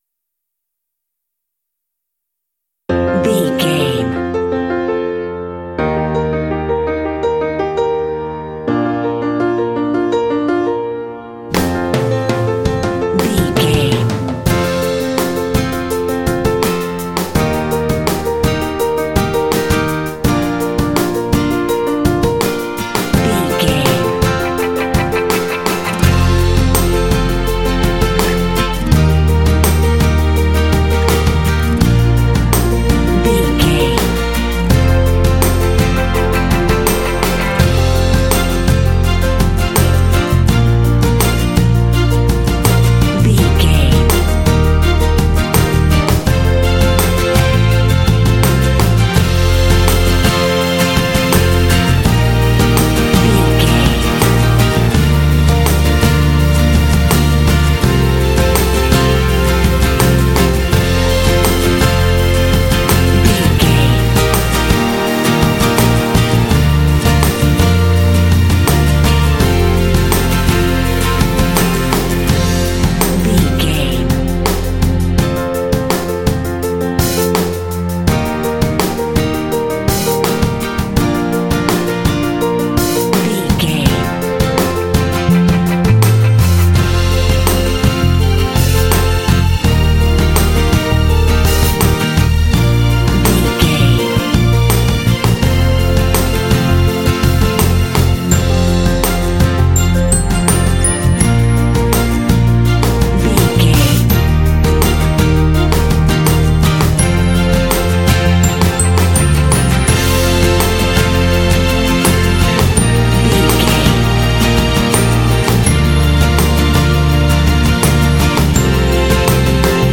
Uplifting
Ionian/Major
motivational
driving
piano
strings
drums
acoustic guitar
bass guitar
indie
contemporary underscore